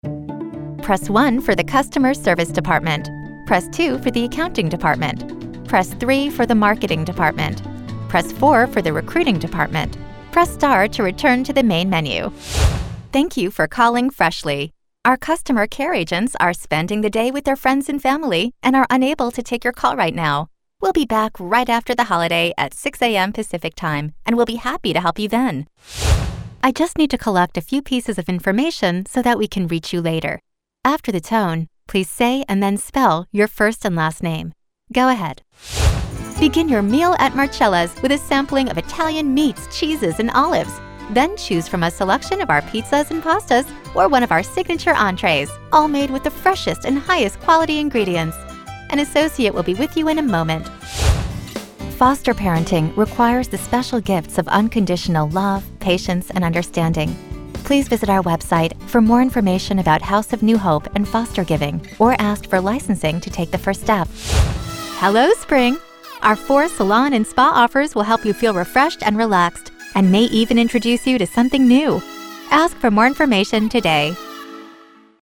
Female Voice Over, Dan Wachs Talent Agency.
Real, Conversational, Confident, Sincere.
IVR